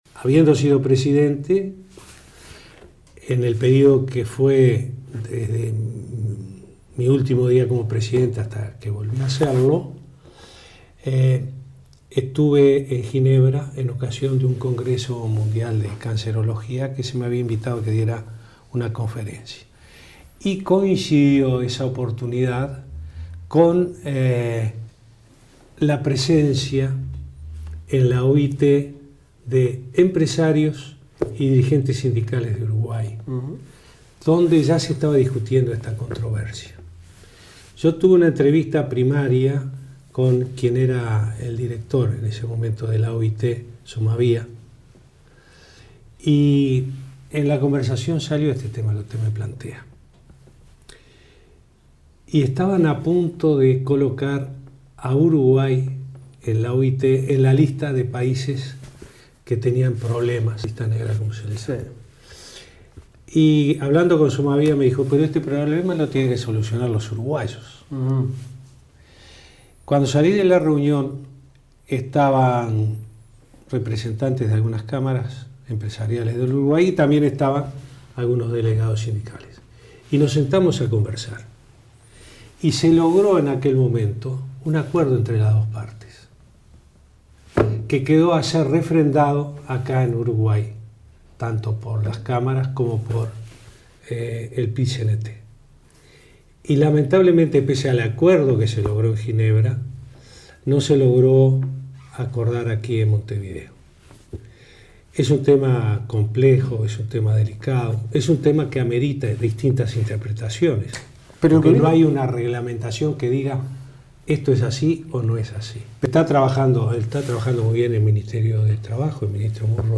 Adelantó al programa "En la Mira" de VTV que dará el discurso inaugural de la 106.ª reunión de la Conferencia Internacional del Trabajo, el 5 de junio en Ginebra.